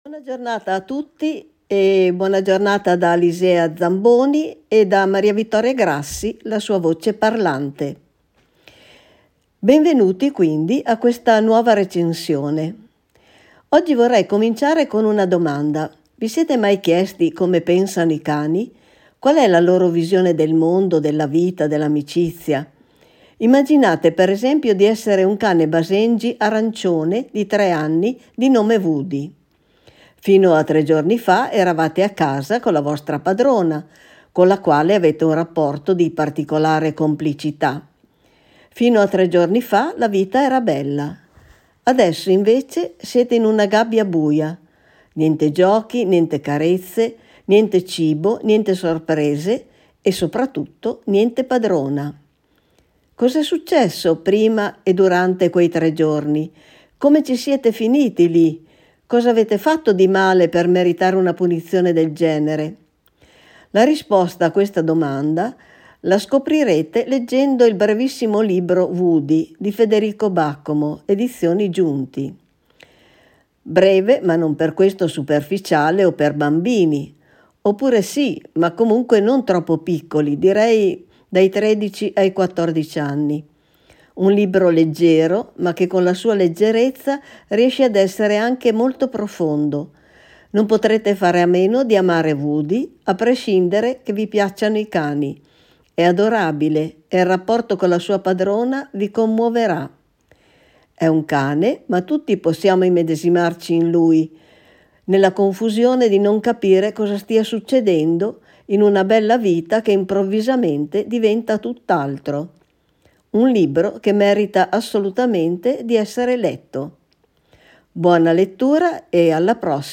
Voce parlante